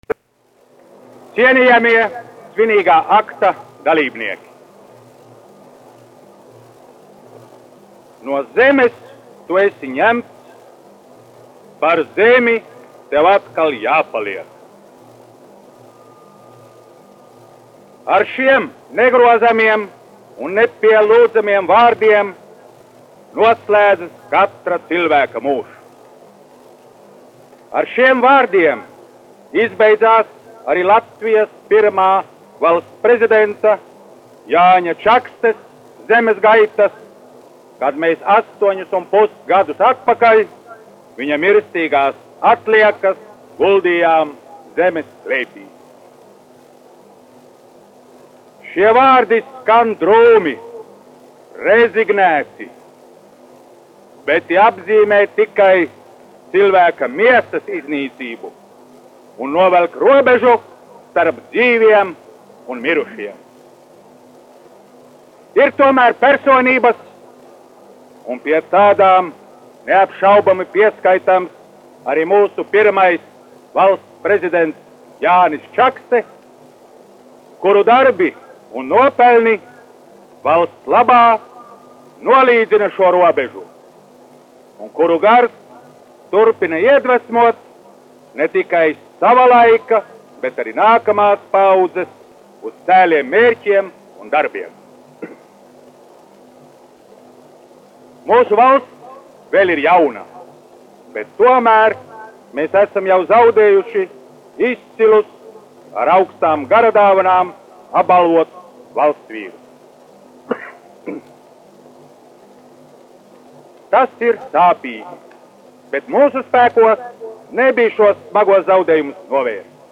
1 skpl. : analogs, 78 apgr/min, mono ; 25 cm
Valsts prezidenta Alberta Kvieša runa pieminekļa atklāšanā pirmajam Valsts prezidentam Jānim Čakstem 1935.g. 14. septembrī (Pārraidījums no Meža kapiem Rīgā) [no žurnāla "Radiofona programma" (1934-1935)]